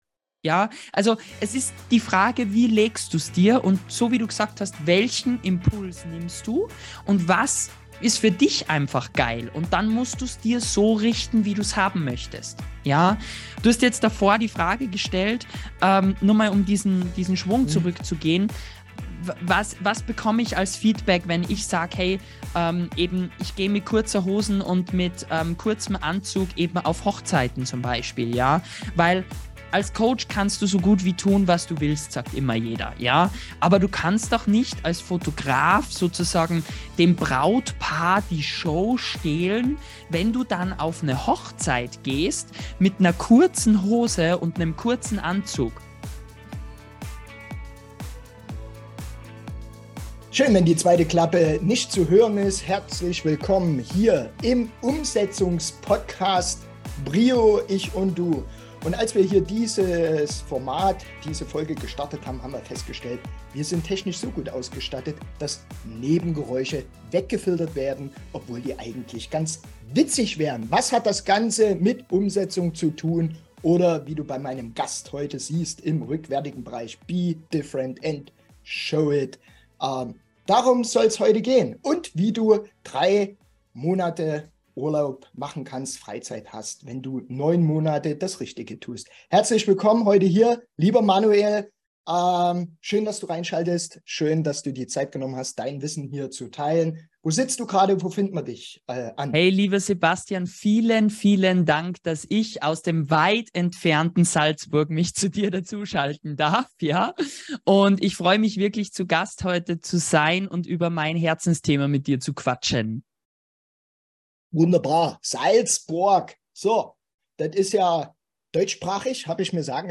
Achso: Bleib bis zum Schluss dran, denn am Ende dieser Folge gibt es noch einen zusätzlichen „Outtake“-Lacher.